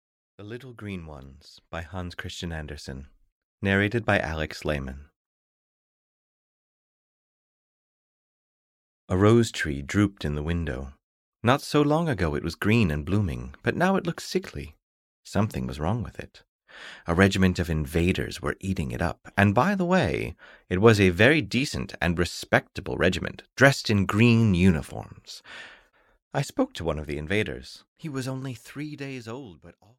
The Little Green Ones (EN) audiokniha
Ukázka z knihy